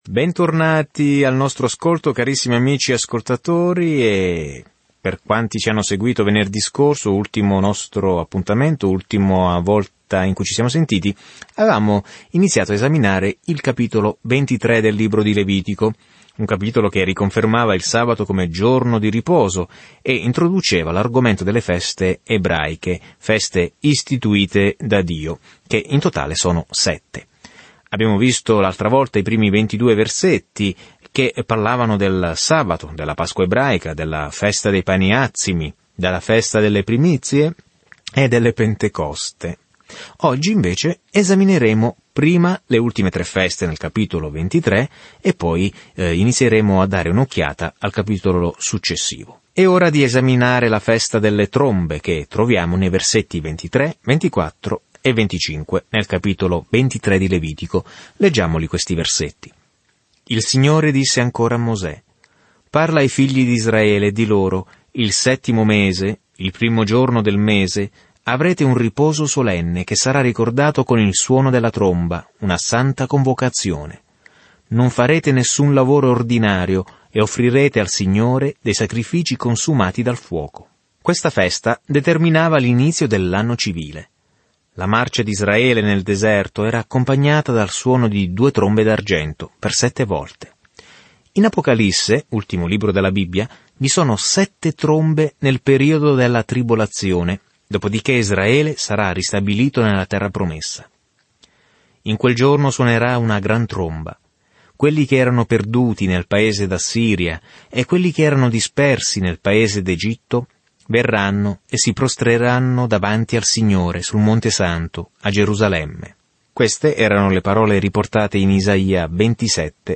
Viaggia ogni giorno attraverso il Levitico mentre ascolti lo studio audio e leggi versetti selezionati della parola di Dio.